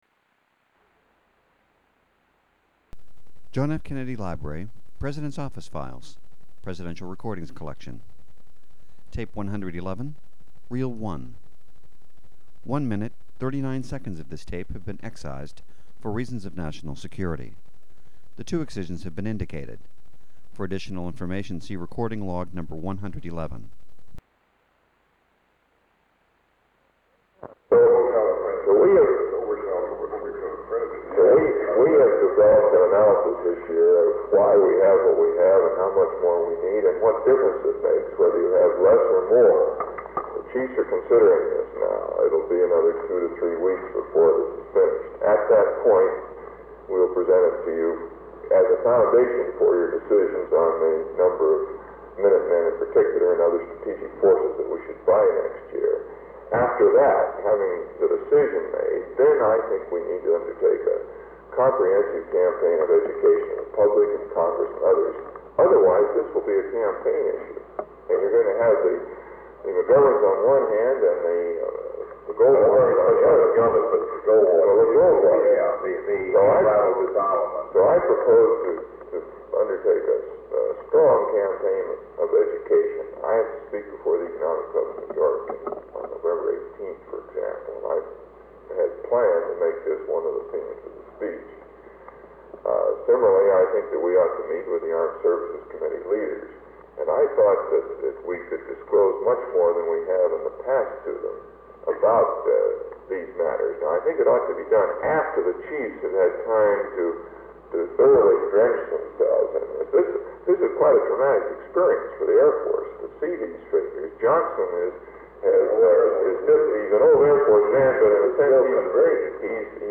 Sound recording of part of a National Security Council (NSC) meeting held on September 12, 1963.
Secret White House Tapes | John F. Kennedy Presidency Meetings: Tape 111.